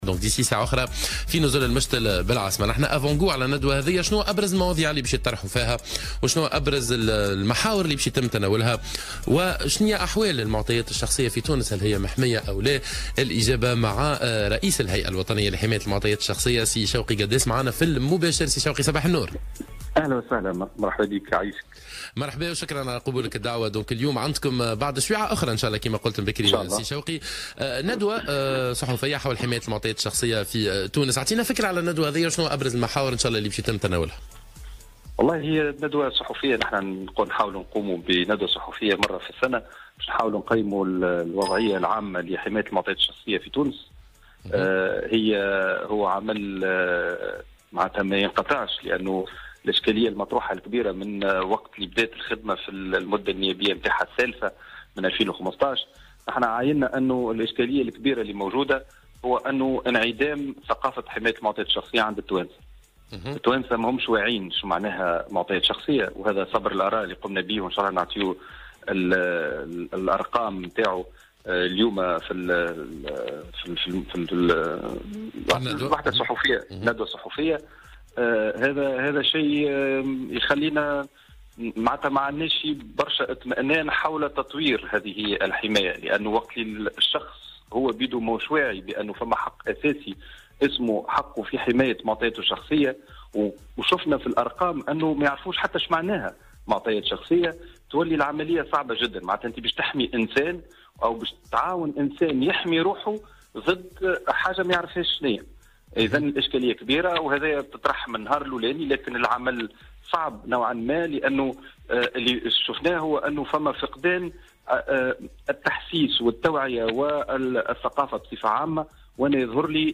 أكد رئيس هيئة حماية المعطيات الشخصية شوقي قداس في مداخلة له على الجوهرة "اف ام" صباح اليوم الثلاثاء أن الهيئة ليست مطمئنة بخصوص تطوير منظومة حماية المعطيات الشخصية في تونس لأن التونسي غير واع بحقه في هذه الحماية أو مفهومها وفق قوله.